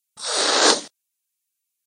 PixelPerfectionCE/assets/minecraft/sounds/mob/cat/hiss1.ogg at ca8d4aeecf25d6a4cc299228cb4a1ef6ff41196e
hiss1.ogg